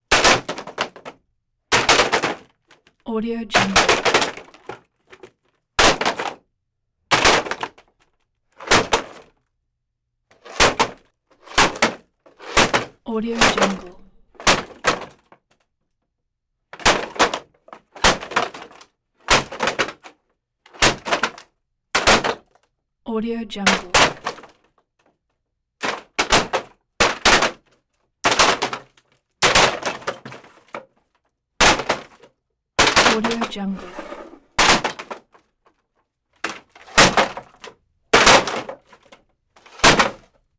Wood Hits - Bamboo Large Two Throw Series x28 Cement Conc royalty free audio track is a great option for any project that requires miscellaneous sounds and other aspects such as a bamboo, large and two.
Sample rate 16-Bit Stereo, 44.1 kHz
افکت صوتی شکستن چوب بامبو